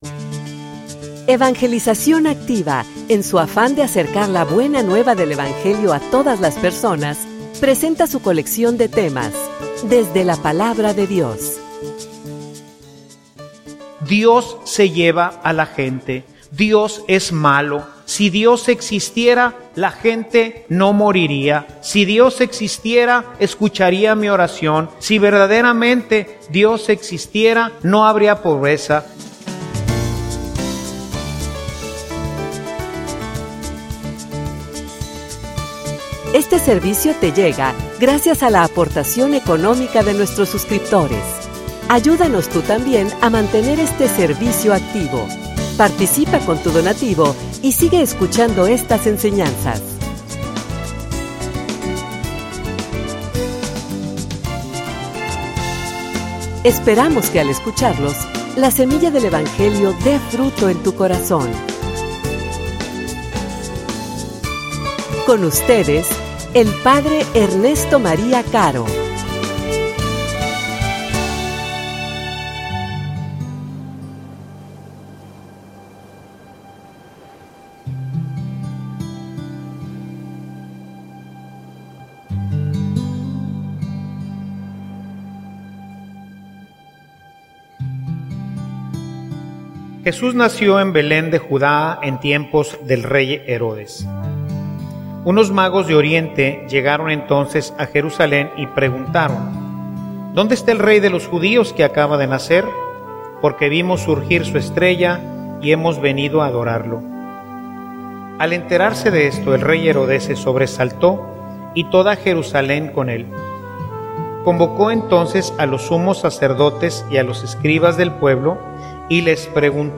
homilia_Mi_alma_te_busca_Senor.mp3